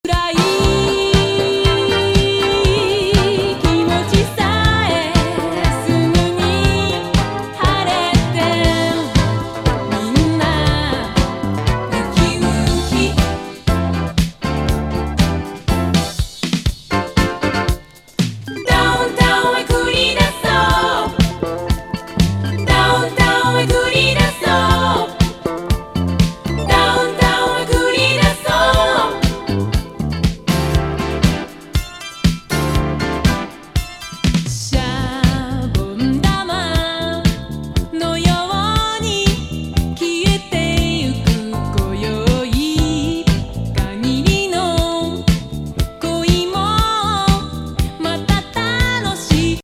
シティ・ディスコティーク・カバー!